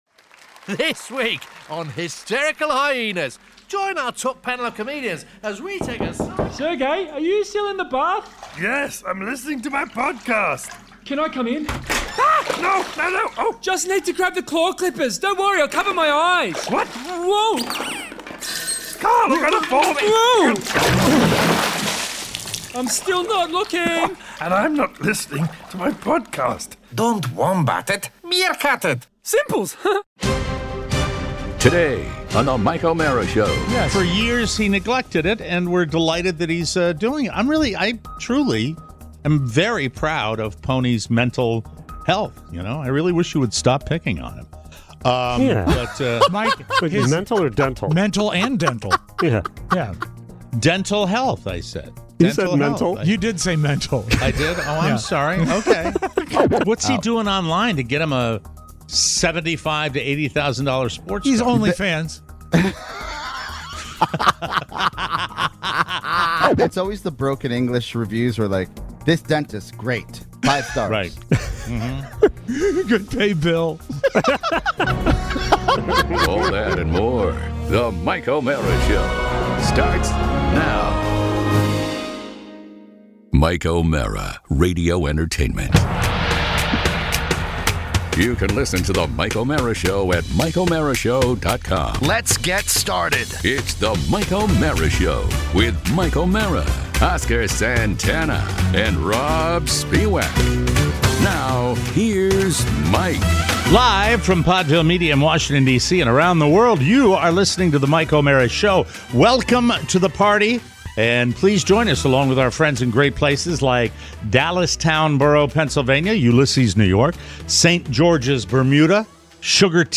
We broadcast from three states today… and Mike has found some amazing vintage audio on his laptop.